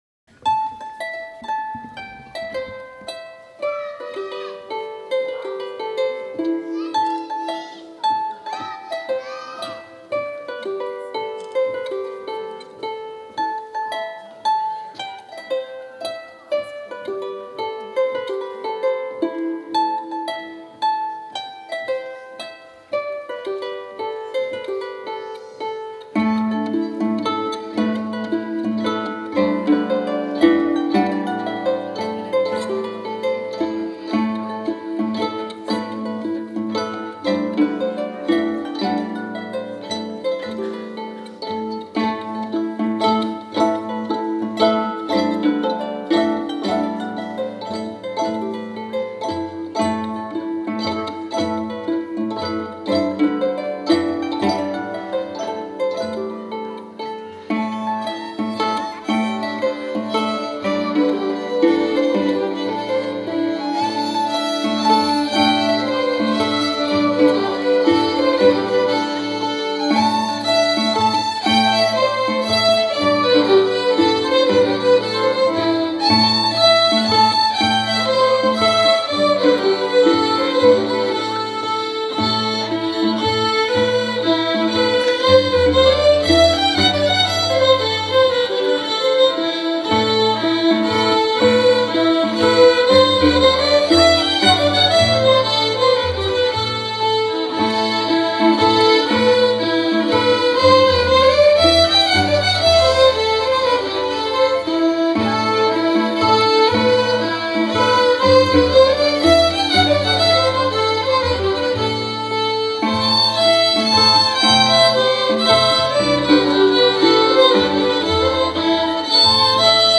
02_harpes_violons.mp3